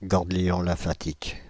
Ääntäminen
Ääntäminen Paris: IPA: [gɑ̃.gljɔ̃ lɛ̃.fa.tik] France (Île-de-France): IPA: /gɑ̃.gljɔ̃ lɛ̃.fa.tik/ Haettu sana löytyi näillä lähdekielillä: ranska Käännös 1.